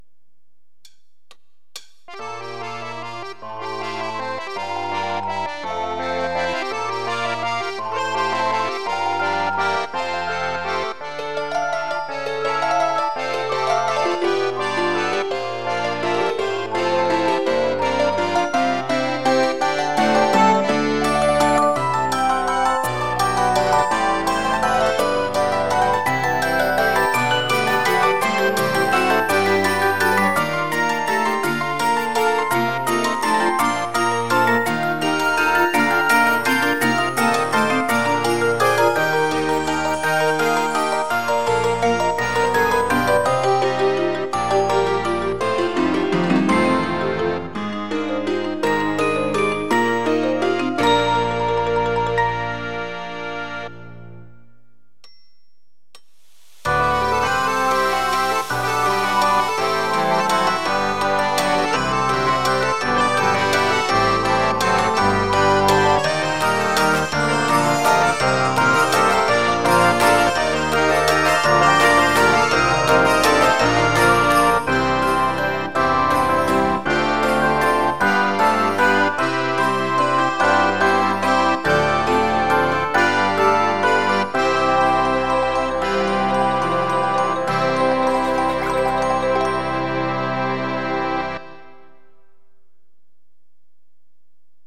珍しく、ほぼ全体的に楽しい成分で構成されている。